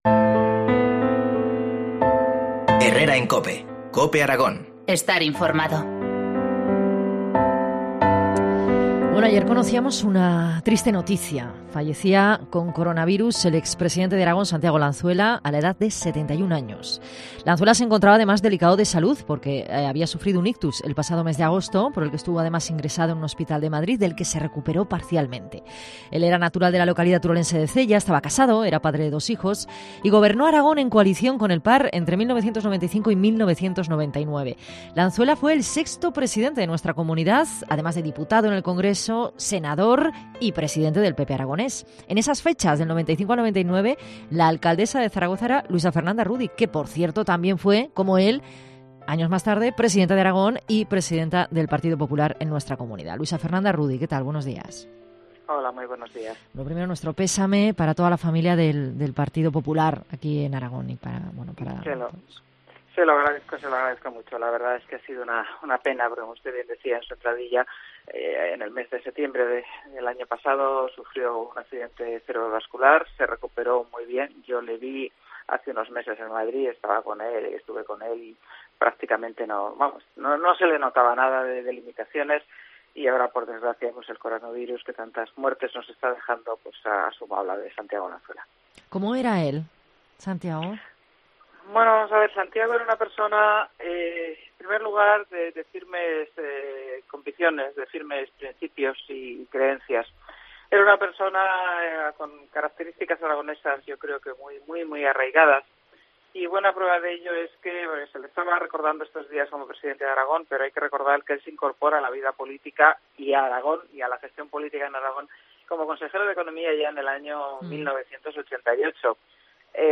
Entrevista a Luisa Fernanda Rudi. 17-04-20